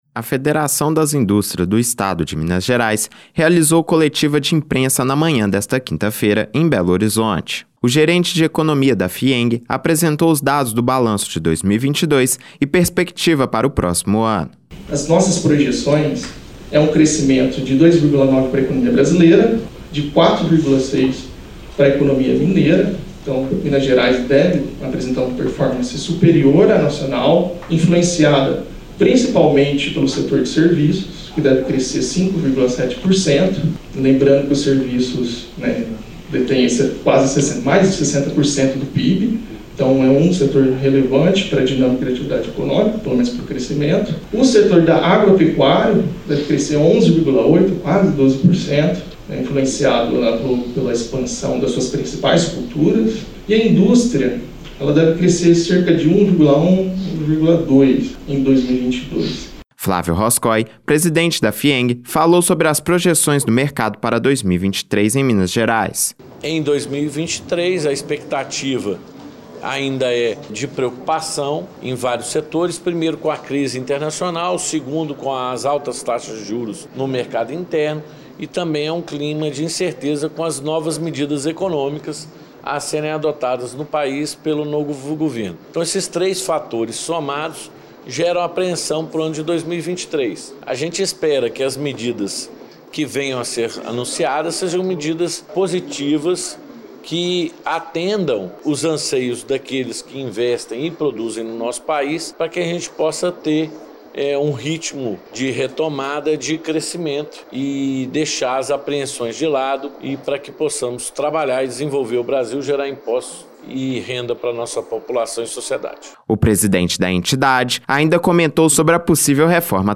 BALANCO-FIEMG-RADIO.mp3